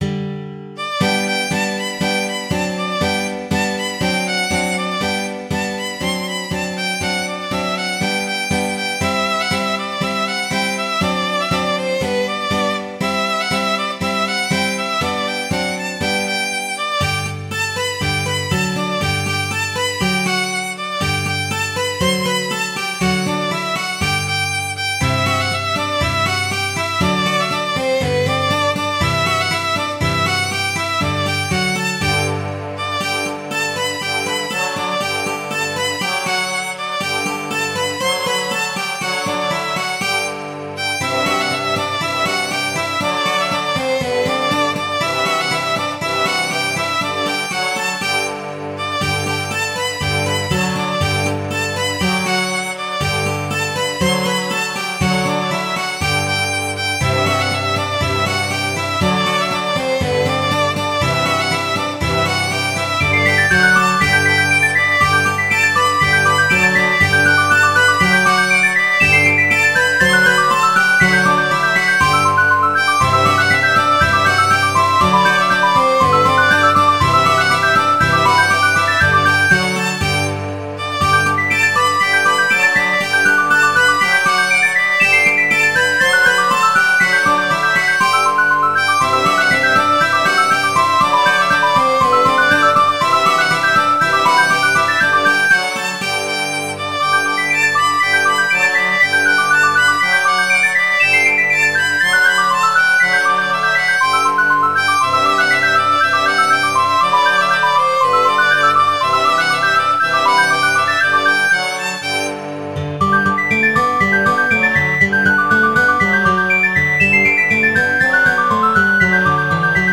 Midi File, Lyrics and Information to Battle of the Kegs